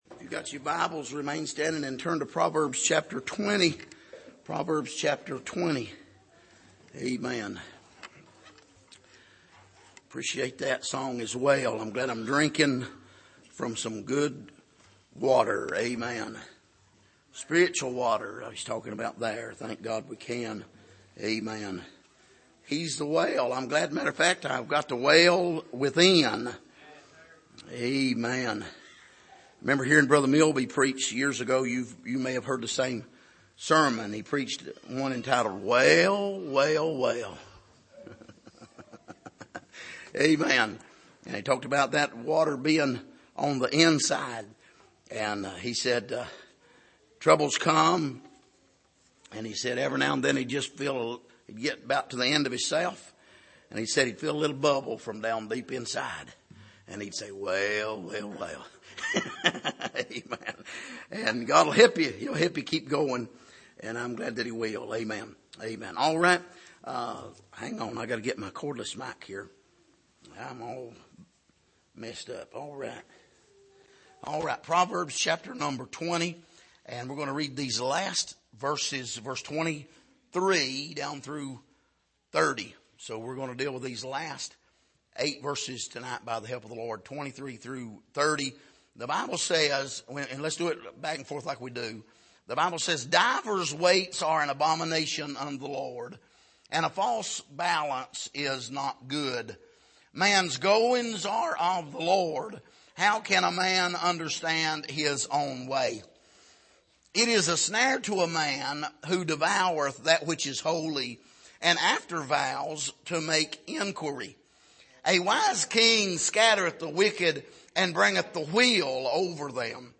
Passage: Proverbs 20:23-30 Service: Sunday Evening